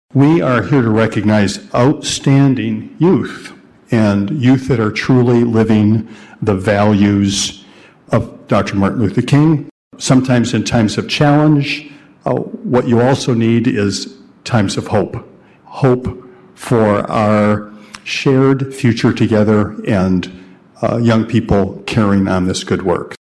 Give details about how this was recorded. KALAMAZOO, MI (WKZO AM/FM) — It was a packed house at Kalamazoo City Hall Monday night as the city commission hosted the presentation of the Annual Social Justice Youth Awards.